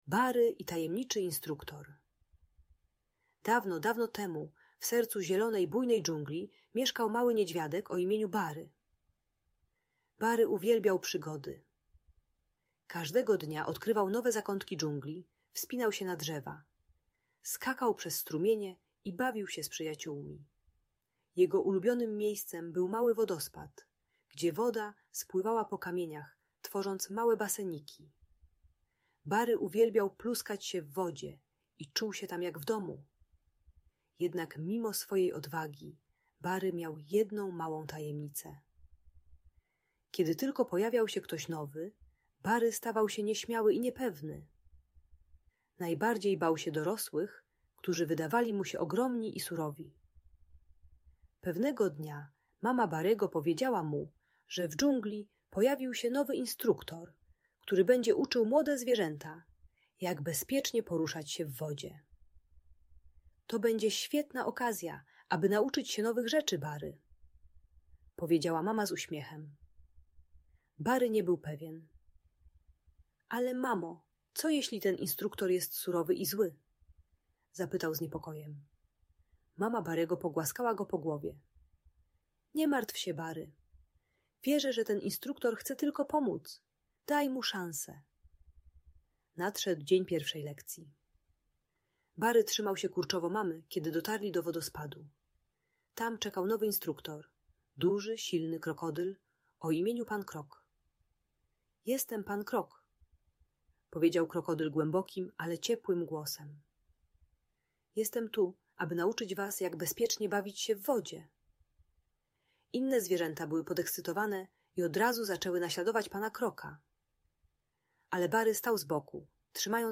Bary i Tajemniczy Instruktor - Lęk wycofanie | Audiobajka